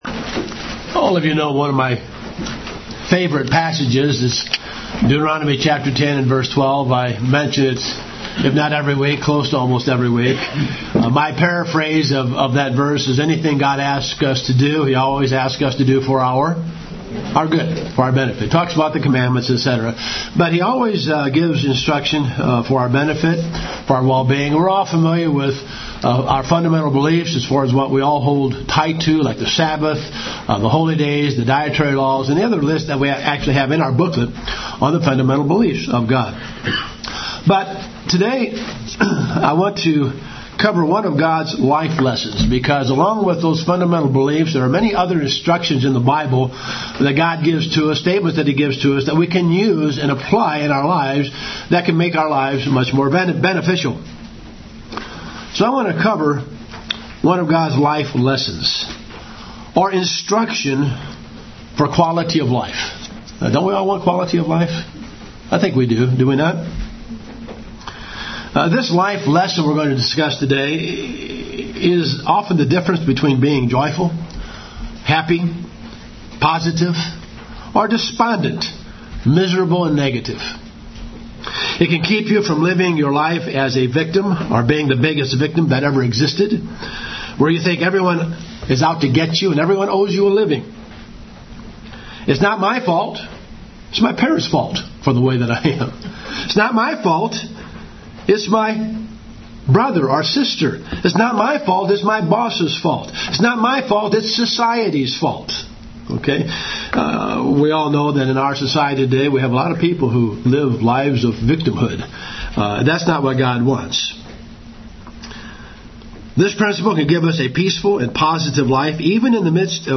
Given in Cincinnati North, OH Dayton, OH